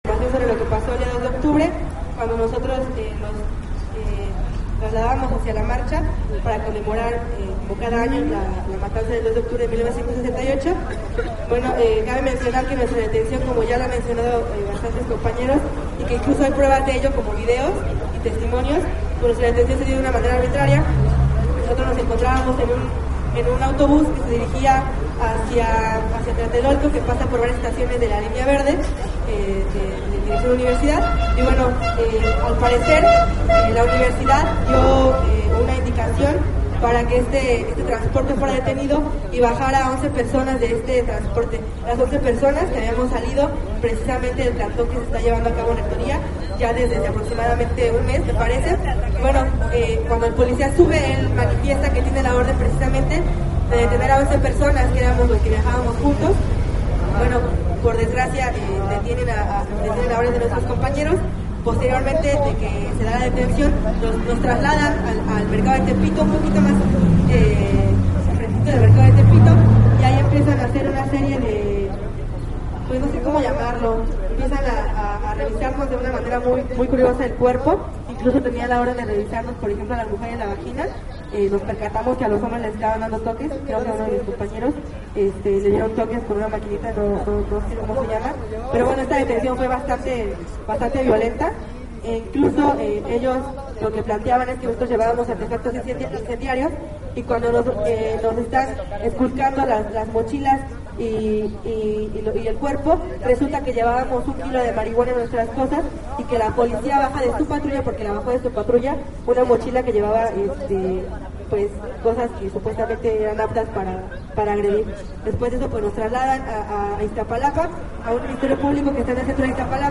Pasado del medio día, inició el mitin que daría paso a la instalación de las carpas para iniciar la huelga de hambre.